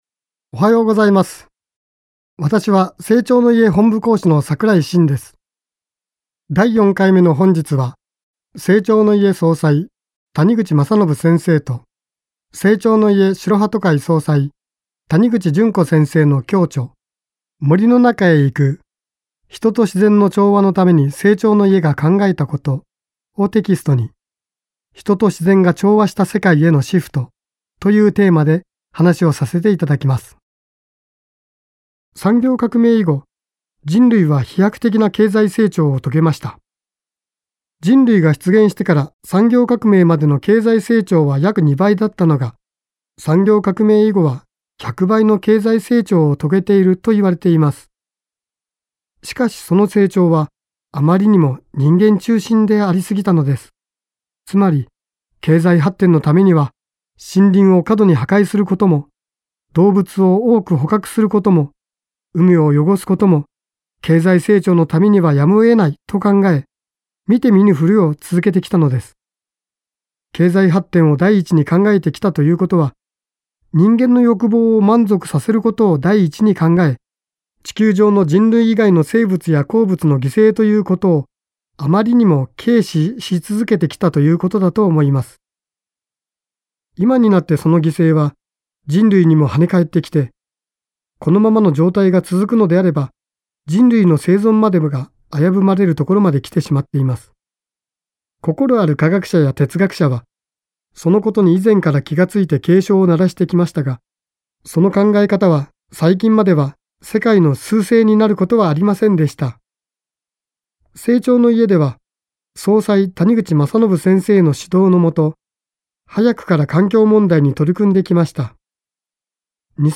生長の家がお届けするラジオ番組。
生長の家の講師が、人生を豊かにする秘訣をお話しします。